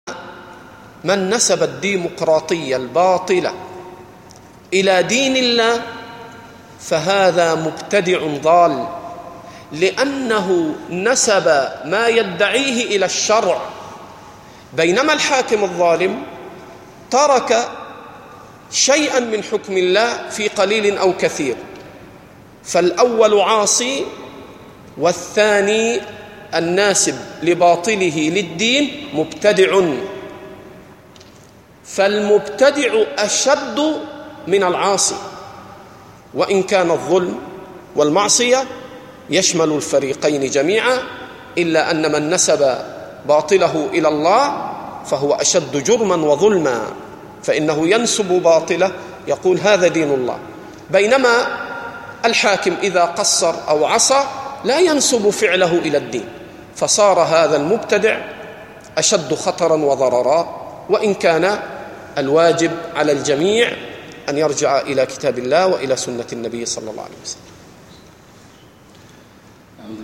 Format: MP3 Mono 44kHz 64Kbps (CBR)